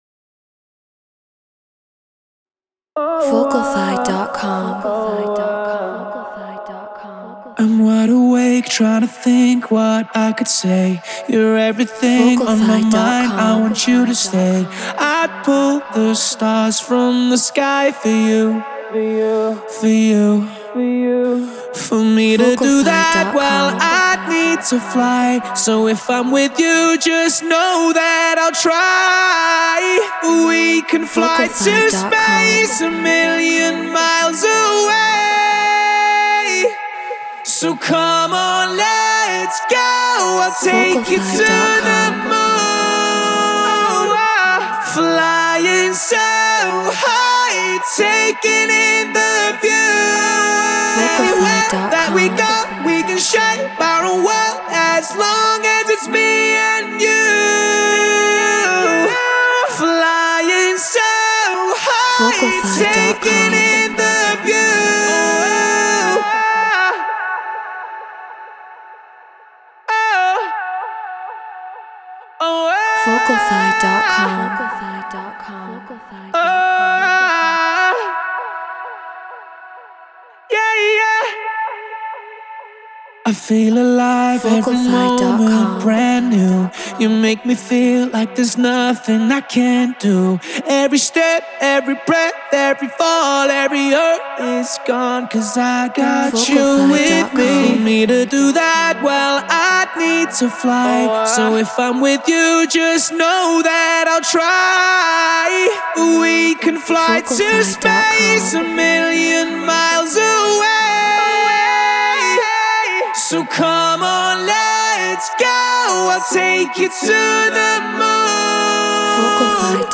Progressive House 130 BPM Amaj
Human-Made